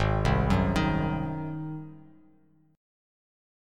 Listen to G#7 strummed